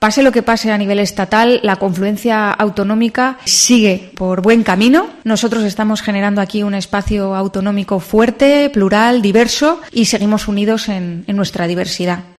De Miguel ha asegurado, como puedes escuchar en nuestro archivo adjunto, que la confluencia 'Unidas por Extremadura (Podemos, IU, Equo y Extremeños) "va por buen camino, pase lo que pase a nivel estatal" por lo que la próxima semana darán detalles acerca de sus listas.